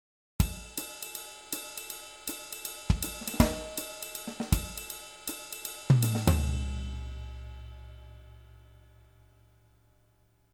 三連跳ねてるリズムの事だよねっ。
ちゃーちゃっちゃ♪ちゃーちゃっちゃ♪ってやつだよねっ？
リズム